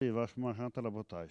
Elle provient de Sallertaine.
Catégorie Locution ( parler, expression, langue,... )